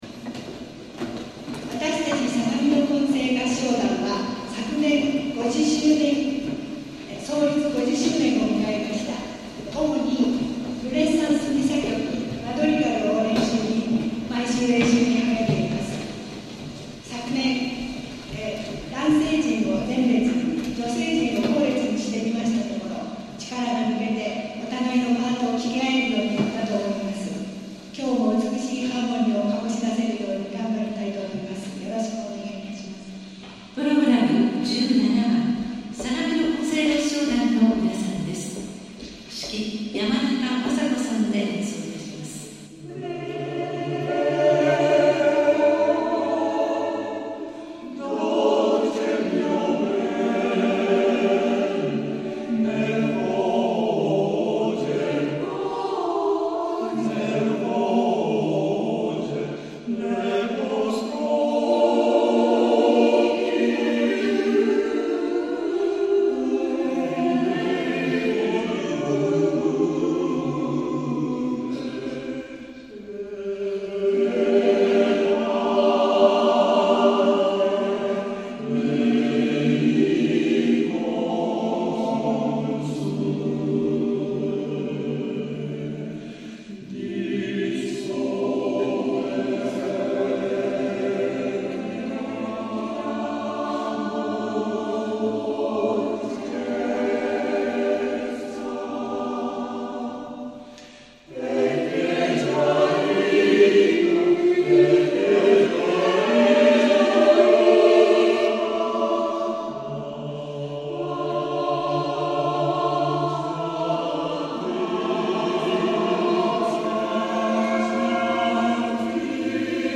神奈川県相模原市で、アカペラのアンサンブルを楽しんでいます。
中世・ルネッサンスのポリフォニーの曲を中心に歌っています。
神奈川県の合唱祭に出演しました
(県立音楽堂)